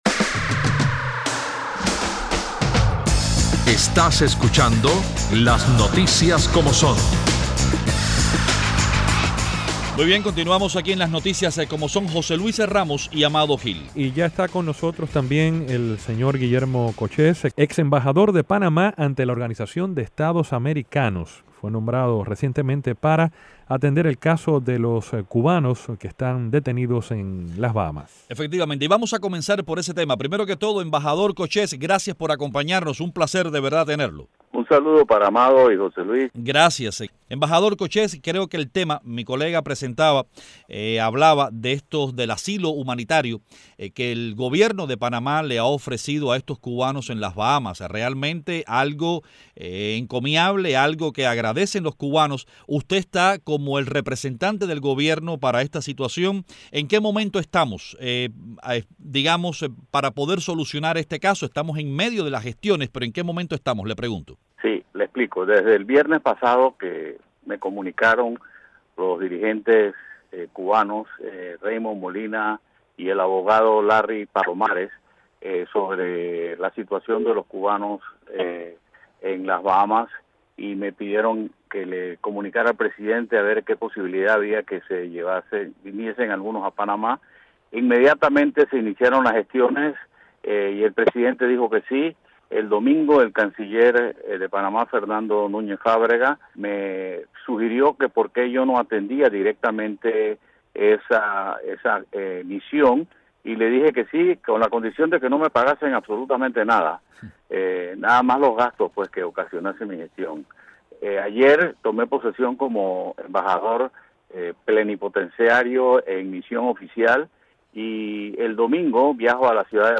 Hoy como invitado Guillermo Cochez, ex embajador de Panamá ante la Organización de Estados Americanos y actualmente embajador plenipotenciario de su país para atender el caso de los 19 ciudadanos cubanos detenidos en las Bahamas, a quienes el gobierno panameño concedió asilo por razones humanitarias.